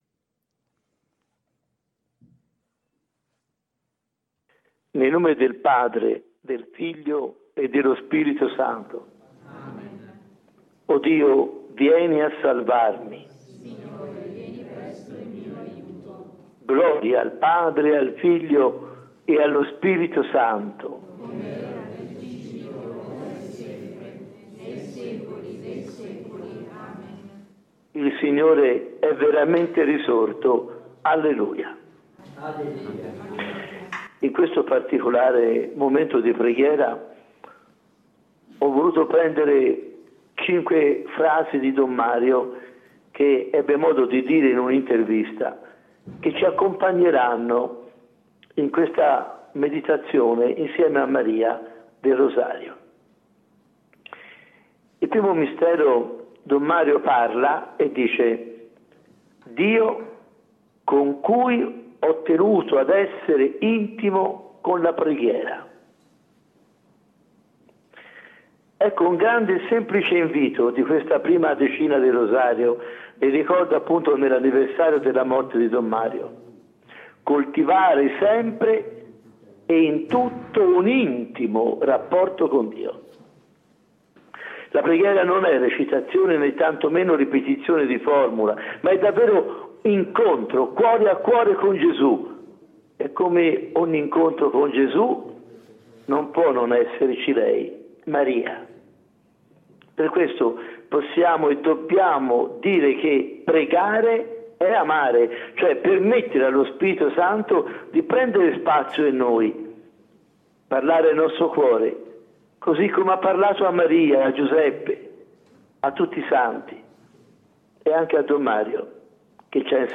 Rosario meditato in diretta